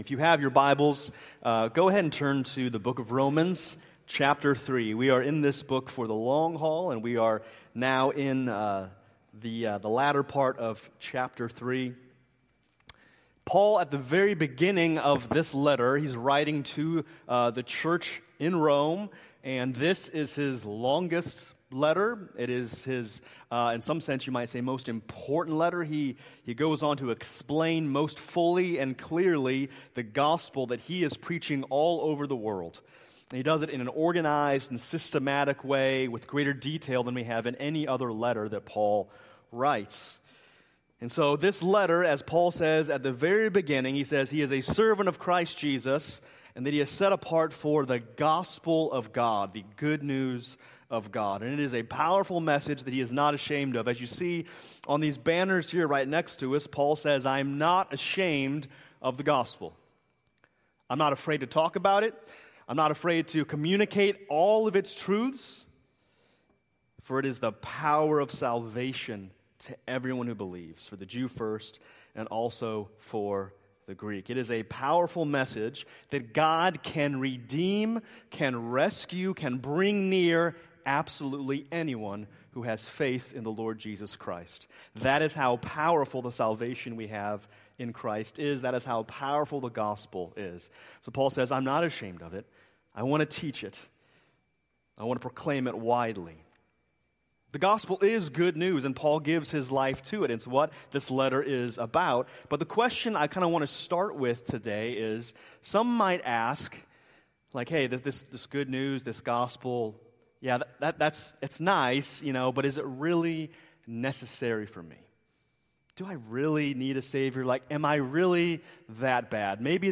October 12, 2025 Worship Service Order of Service: